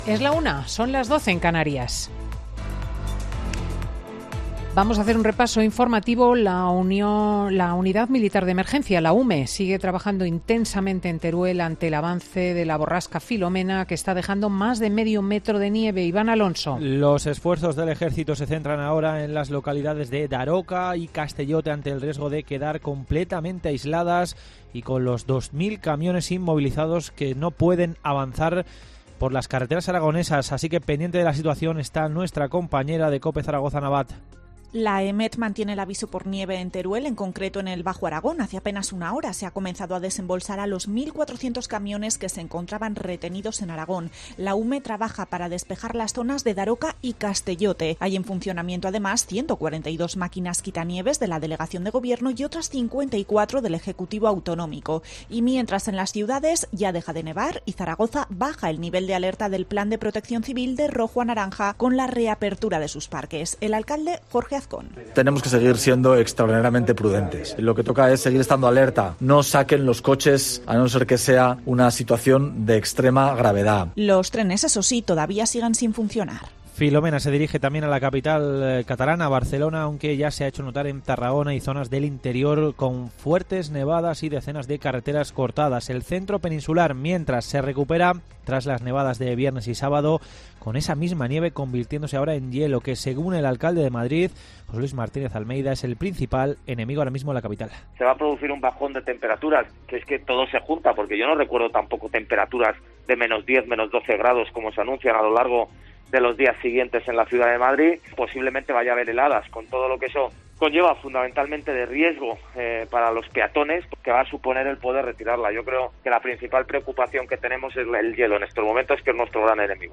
Boletín de noticias COPE del 10 de enero de 2021 a las 13.00 horas